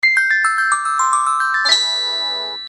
Короткие рингтоны
Рингтоны на смс и уведомления
Звонкие